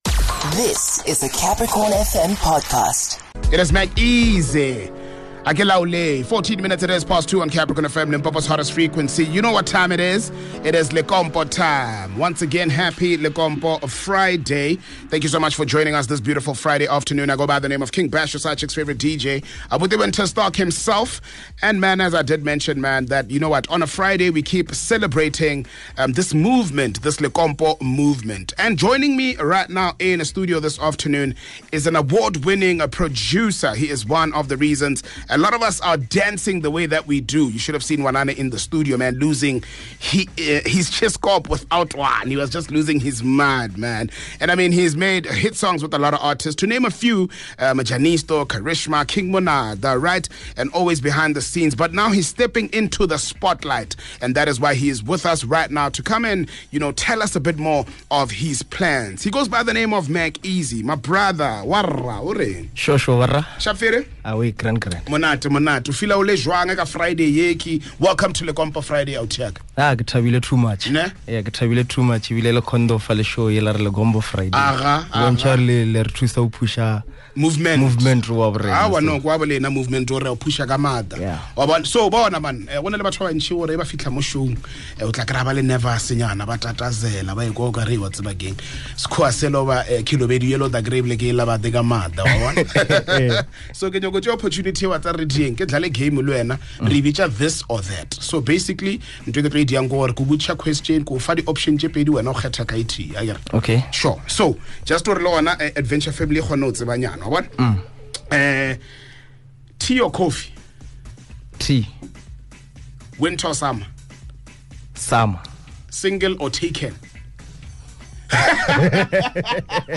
joined in studio